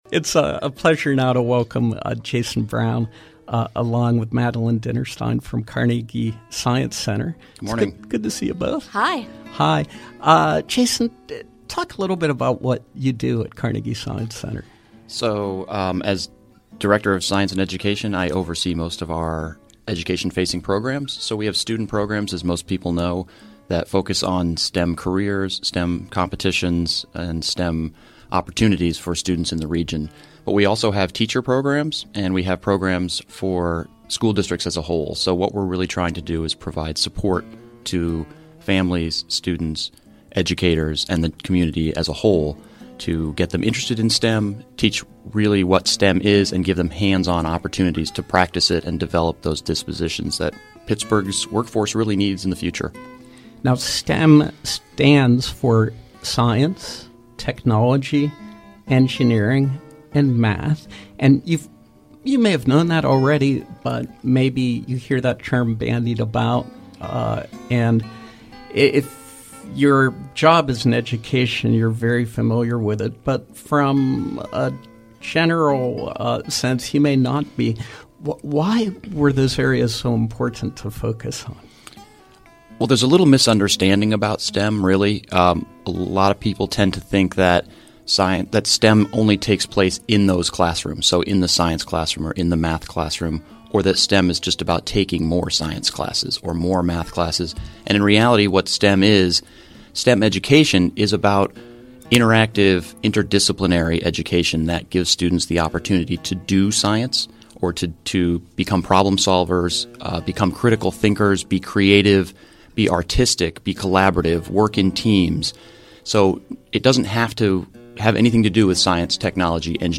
In Studio Pop-Up
Interviews